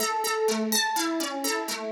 Index of /musicradar/shimmer-and-sparkle-samples/125bpm
SaS_Arp01_125-A.wav